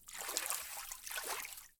fishLine2.wav